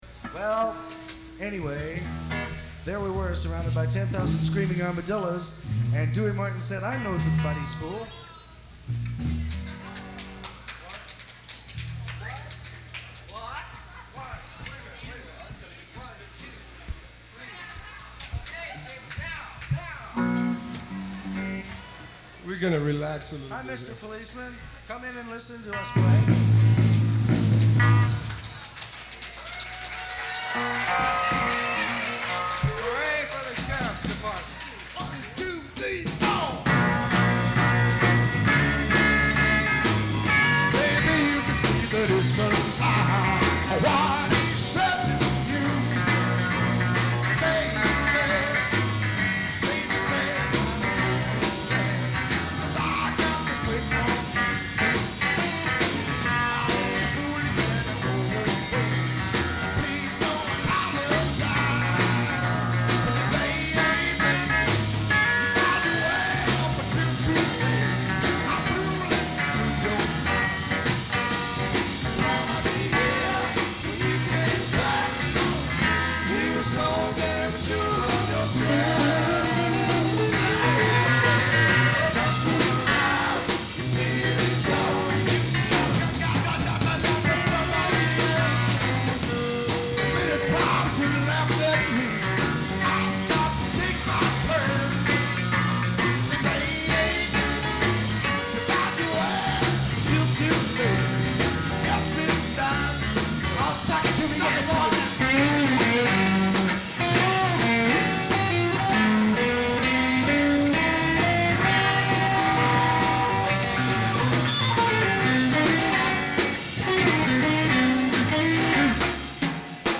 Además, está comprimido en MP3 pero… es lo que hay.